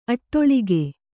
♪ aṭṭoḷige